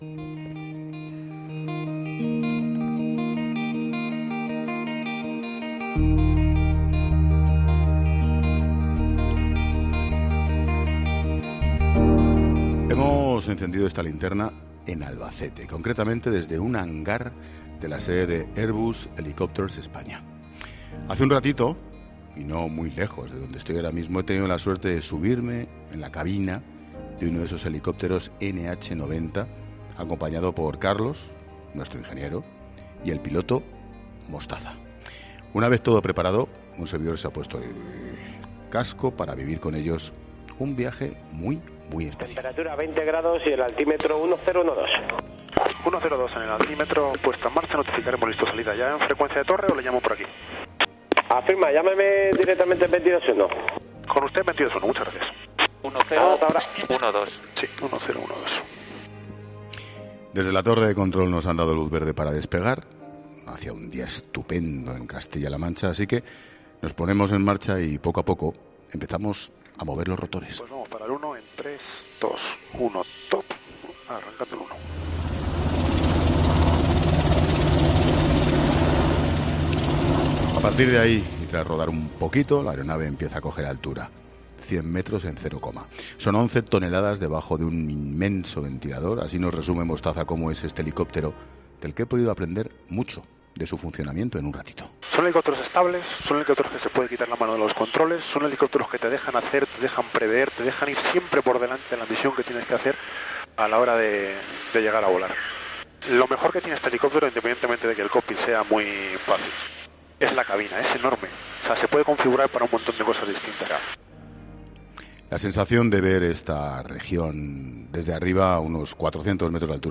Hoy estamos haciendo esta Linterna en Albacete, concretamente desde uno de los hangares de la sede de Airbus Helicopters España.